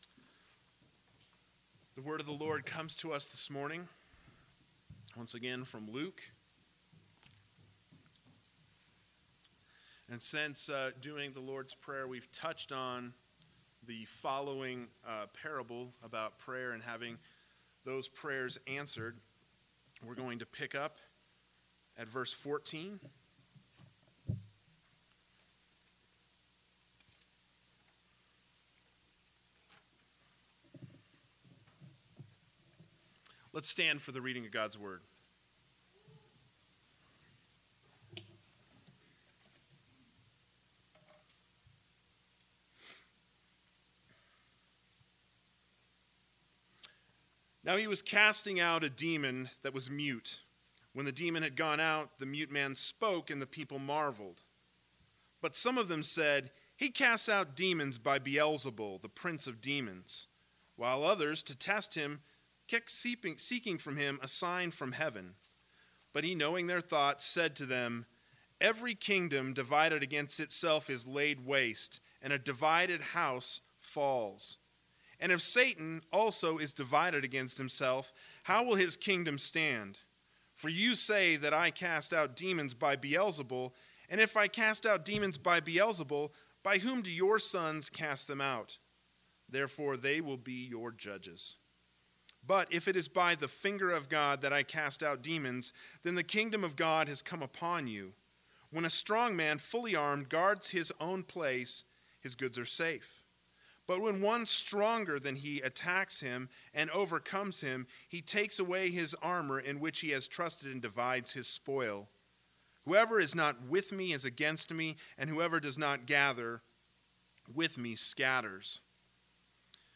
AM Sermon – 08/16/2020 – Luke 11:14-26 – Is The Master of the House In?